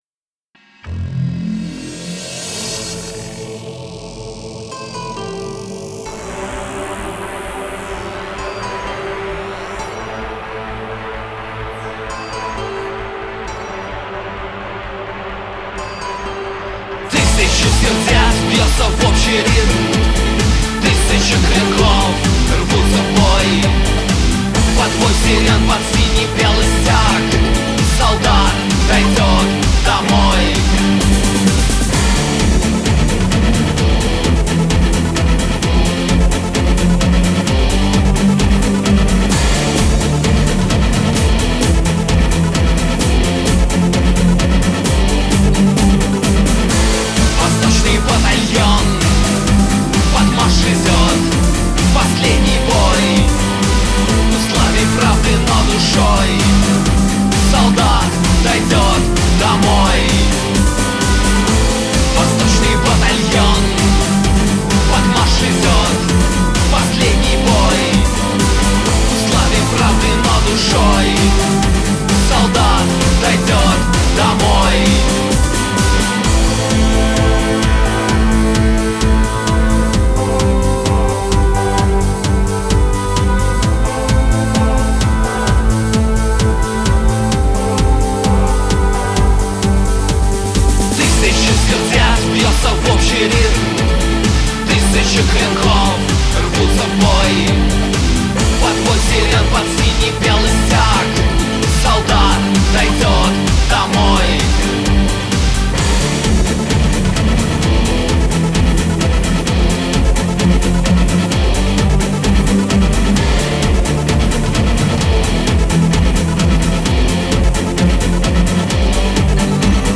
aggro-industrial